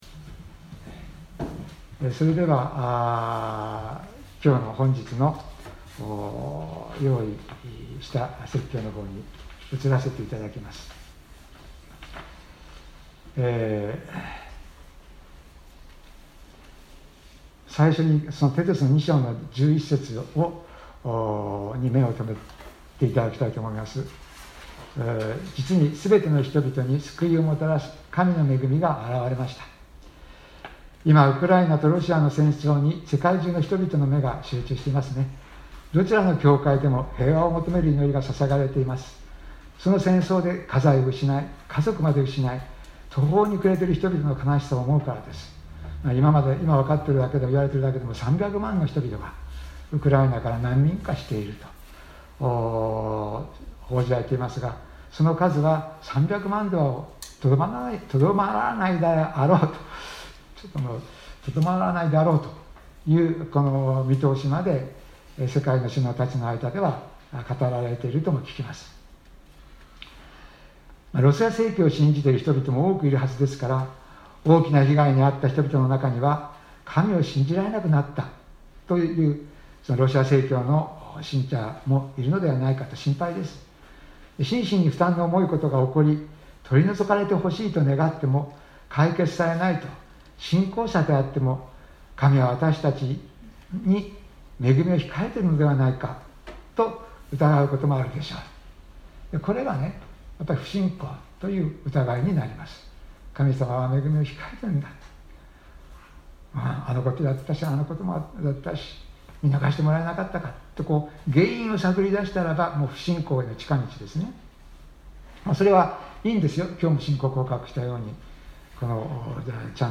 2022年03月27日朝の礼拝「思慮深い生活に生かされる」せんげん台教会
説教アーカイブ。
音声ファイル 礼拝説教を録音した音声ファイルを公開しています。